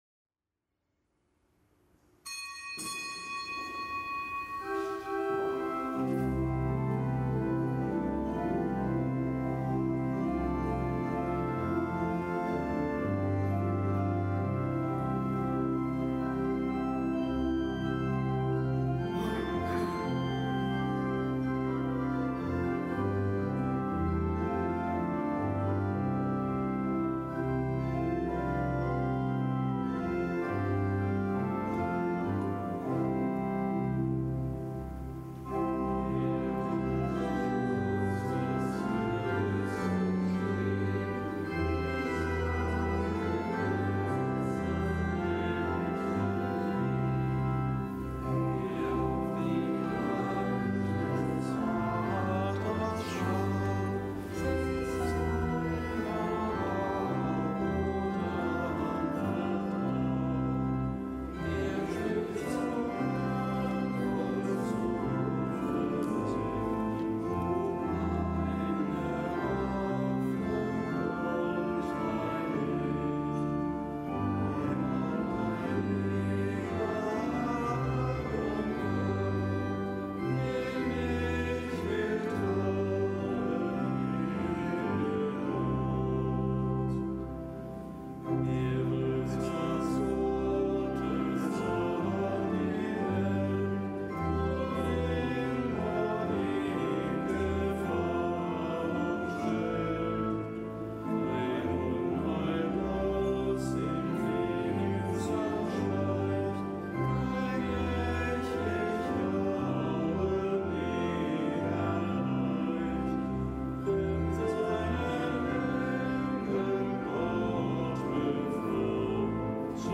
Kapitelsmesse am Montag der vierzehnten Woche im Jahreskreis
Kapitelsmesse aus dem Kölner Dom am Montag der vierzehnten Woche im Jahreskreis, nichtgebotener Gedenktag der Heiligen Willibald, Bischof von Eichstätt, Glaubensbote (RK).